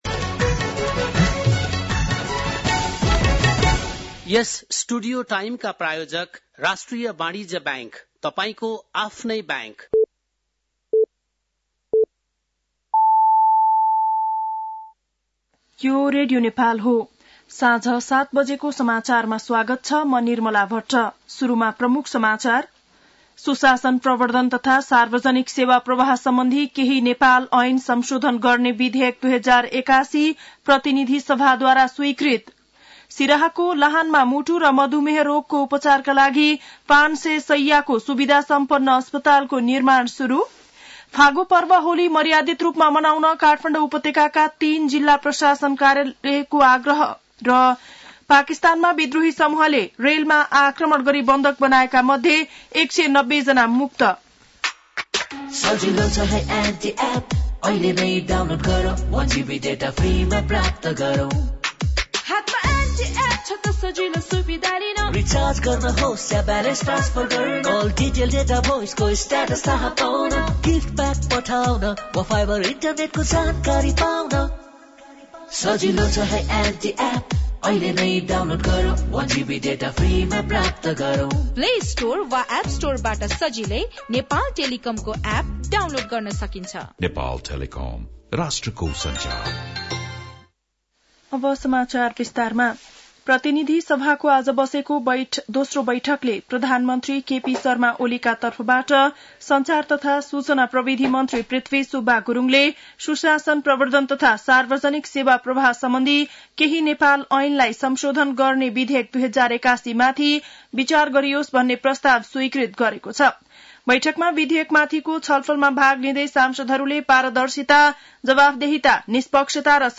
बेलुकी ७ बजेको नेपाली समाचार : २९ फागुन , २०८१